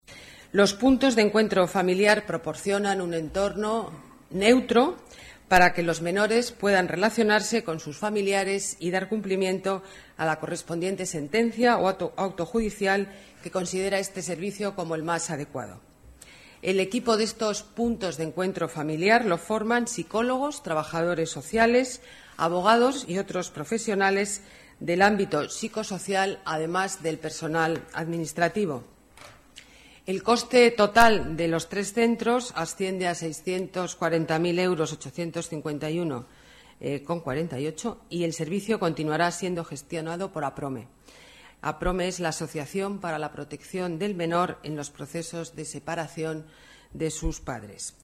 Nueva ventana:Declaraciones alcaldesa Ana Botella: Puntos de Encuentro Familiar